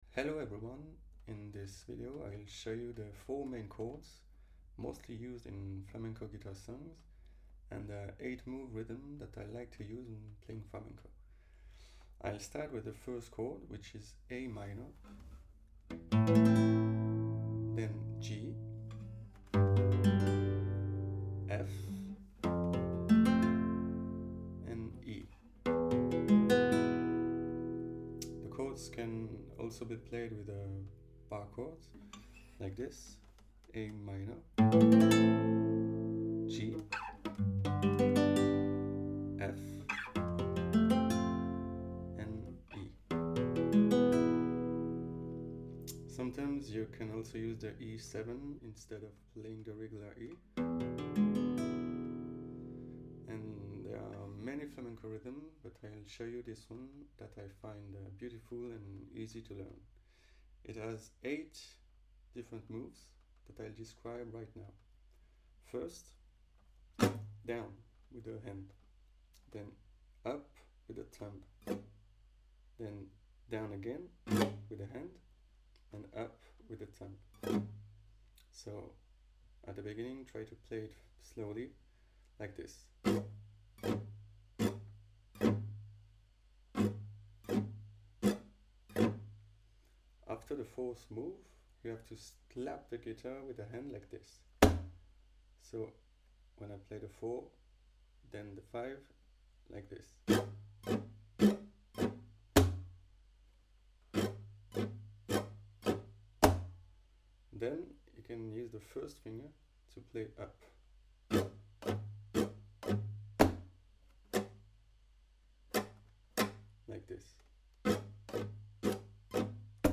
Full flamenco guitar tutorial with